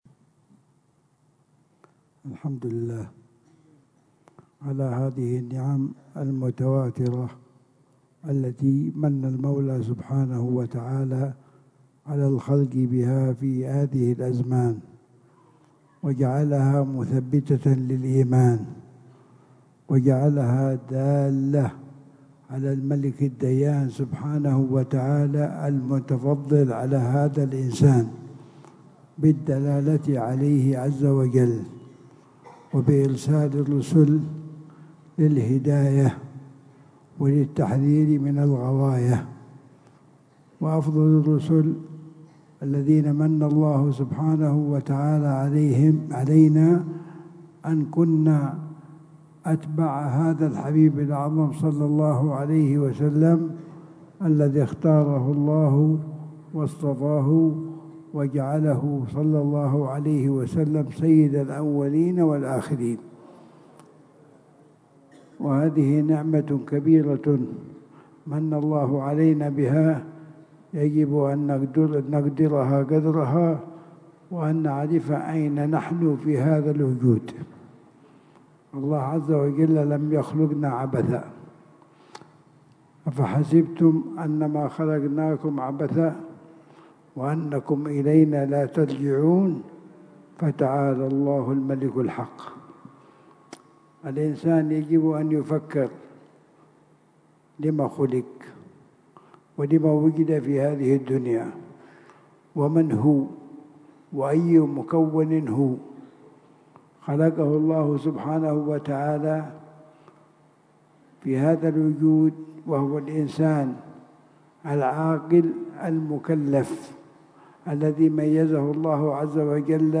محاضرة
في دار المصطفى